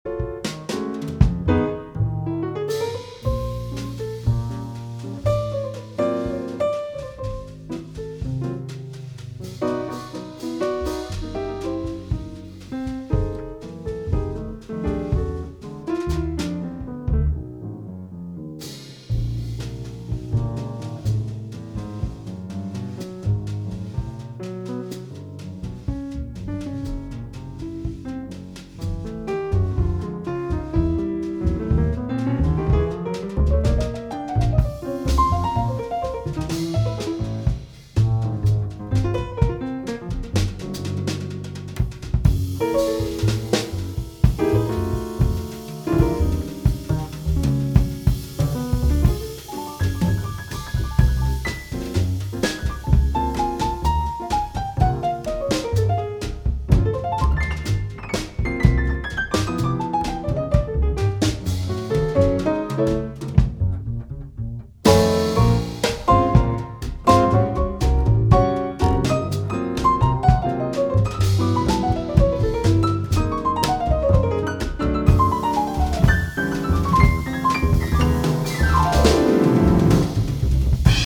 爵士音樂